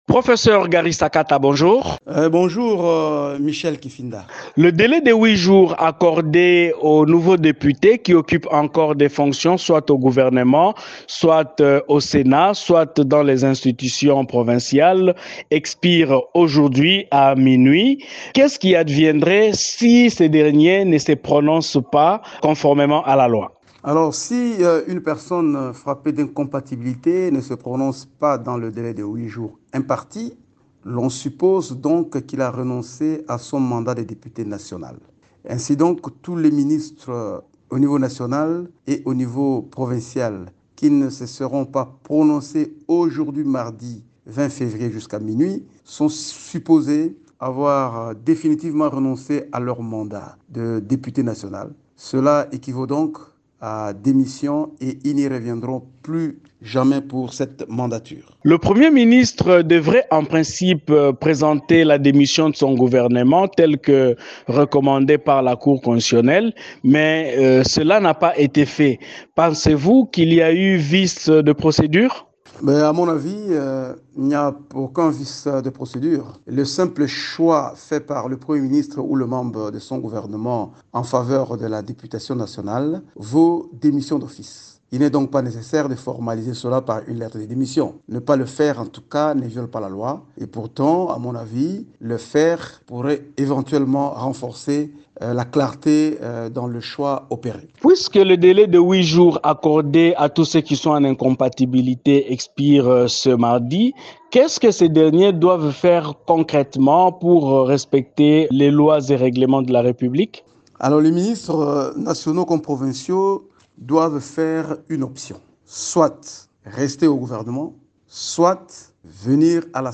Professeur de droit et député national, Gary Sakata a indiqué, ce mardi 20 février, dans un entretien à Radio Okapi que les ministres nationaux, provinciaux et sénateurs frappés d'incompatibilité avec leur mandat électif qui ne se seront pas prononcer, sont supposé avoir définitivement renoncé à leur mandat de député national.